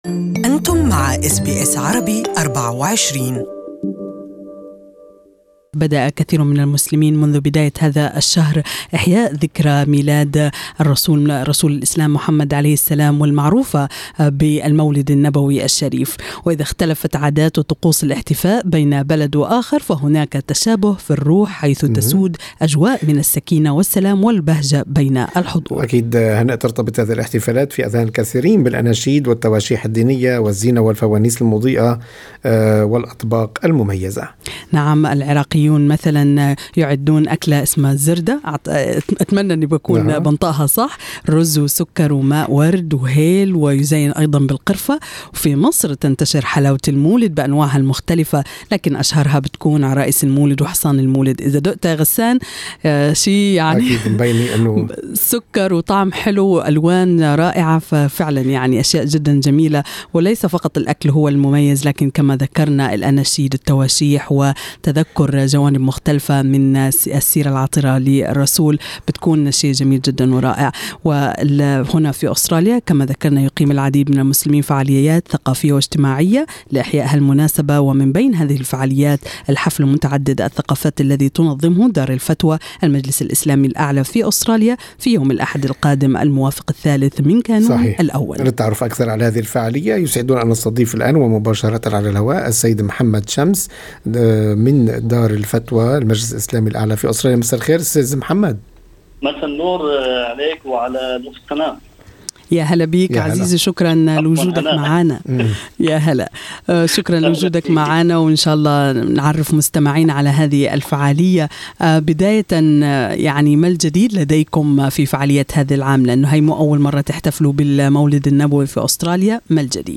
للتعرف اكثر على هذه الفعالية يمكنكم الاستماع الى اللقاء الكامل